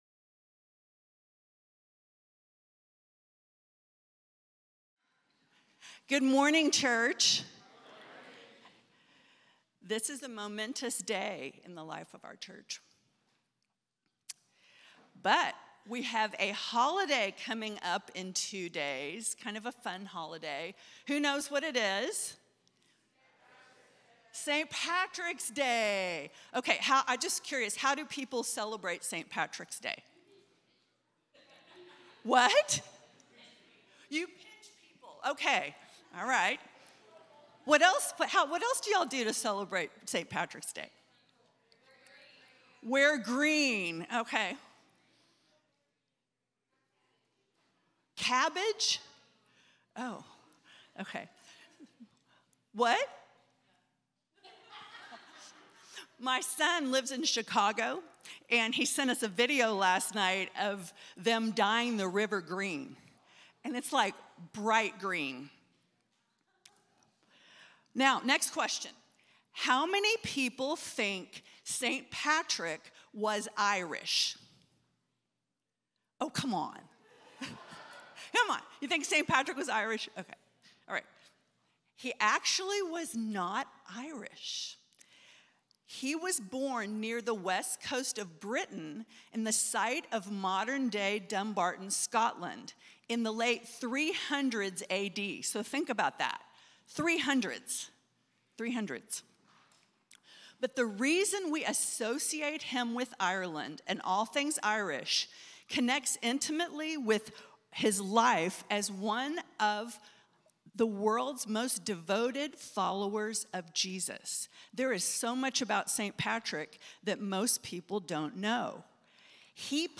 10AM Service Mar 15th 2026